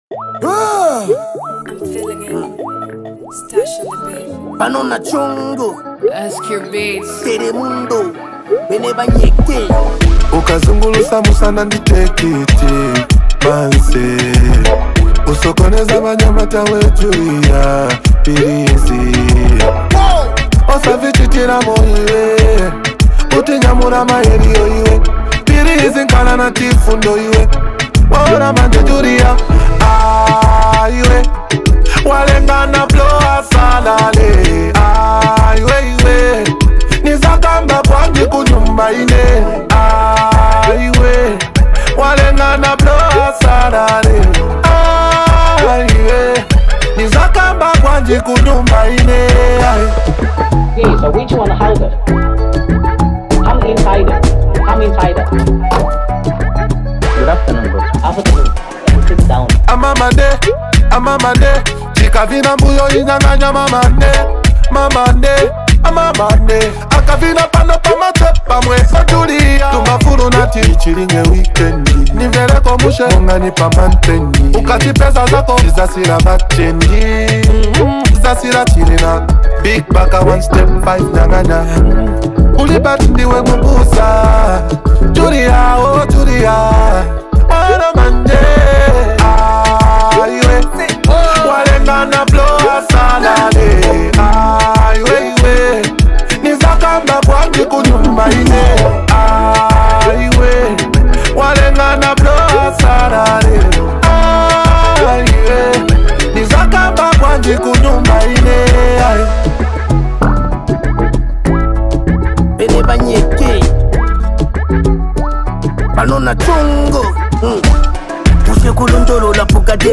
high-energy Zambian hip-hop track
a confident and melodic delivery
humorous punchlines
raw energy and sharp bars
combining infectious beats